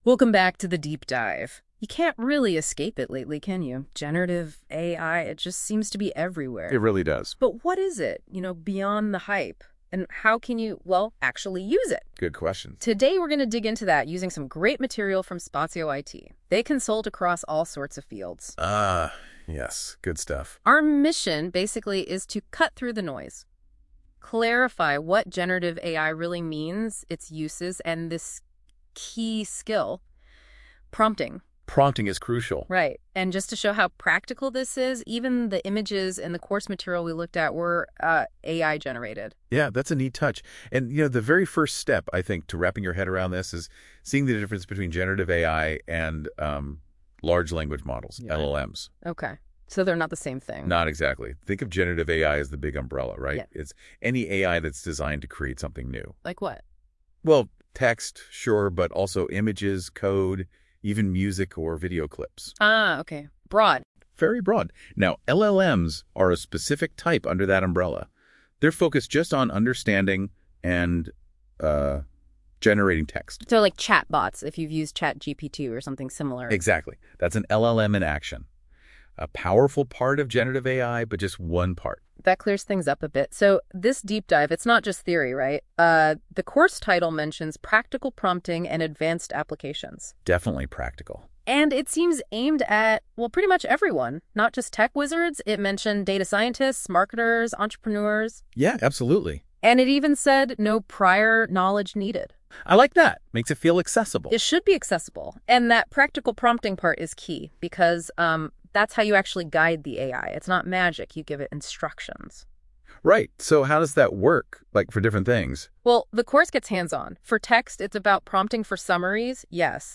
accompanying audio has generated using Google Gemini.